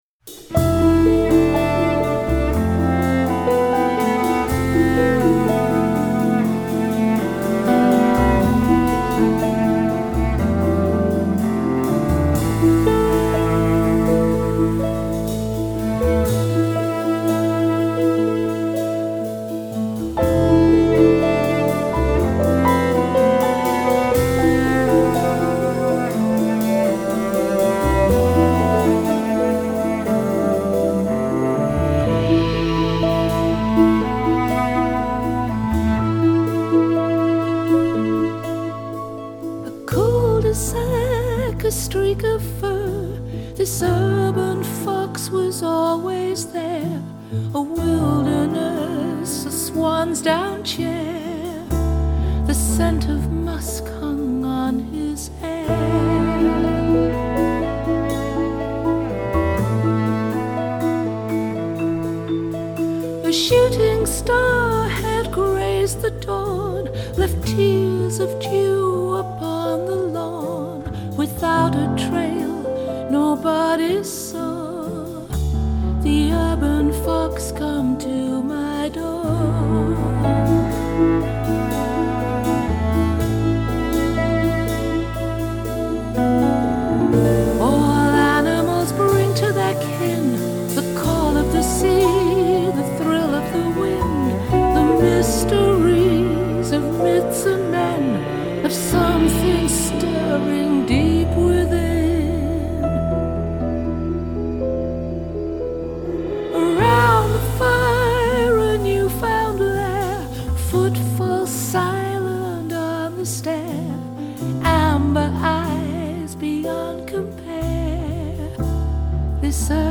totally feral